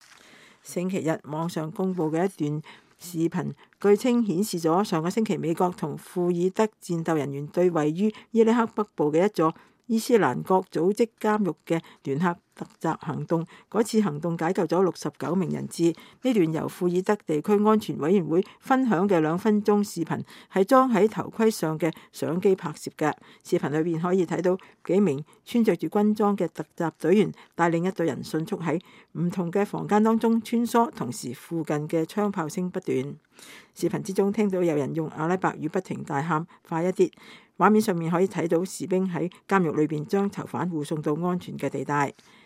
這段由庫爾德地區安全委員會分享的兩分鐘視頻是裝在頭盔上的相機拍攝的。視頻裡可以看到幾名穿著軍裝的突擊隊員帶領一隊人迅速地在不同房間中穿梭，同時，附近槍炮聲不斷。